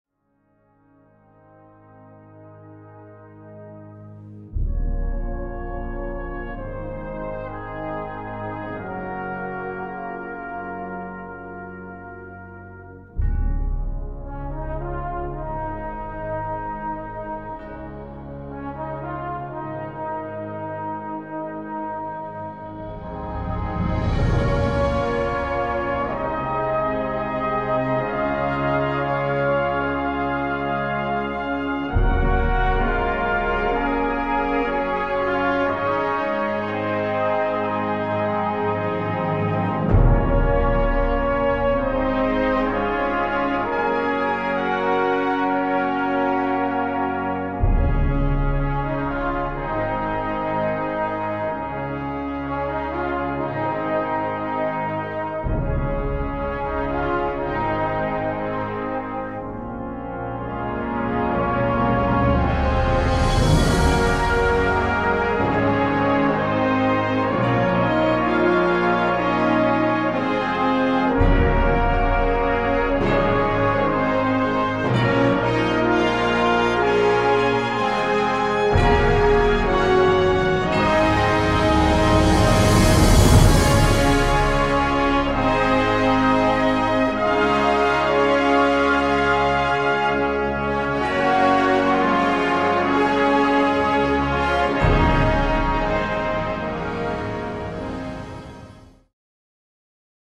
Gattung: Filmmusik für Blasorchester
Besetzung: Blasorchester